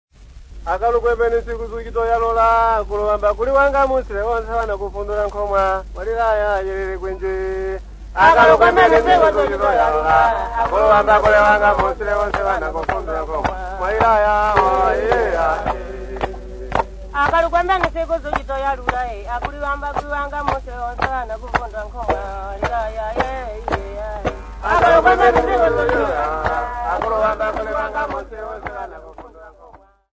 Nsenga Singers
Dance music
field recordings
sound recording-musical
Traditional vocal song accompanied by ulimba xylophone